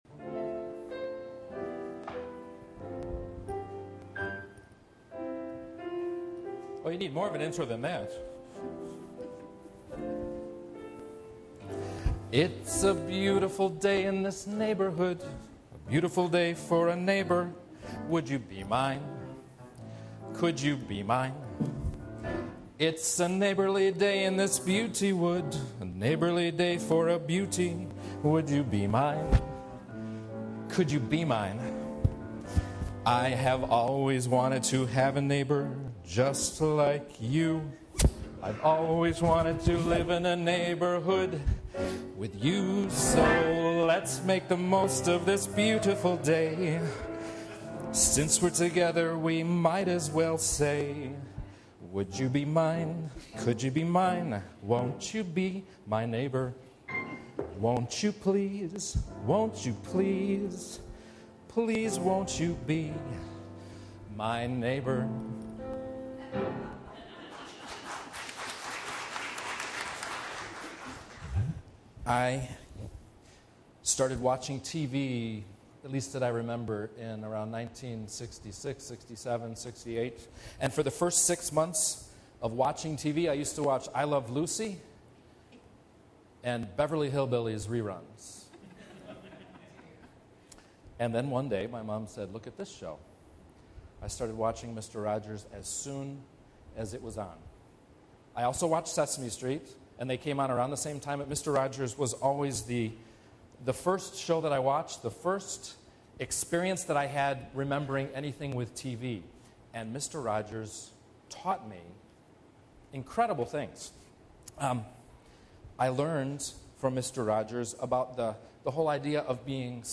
It's also significantly impacted by Trayvon Martin. There's a lot in today's sermon podcast from St. Paul's United Church of Christ in Downers Grove, IL "Neighbor" podcast